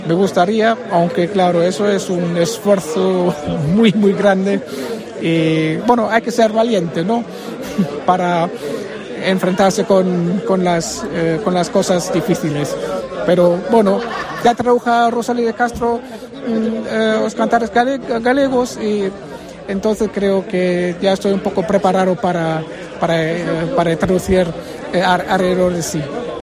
intervención en el acto de entrega del Premio Trasalba 2024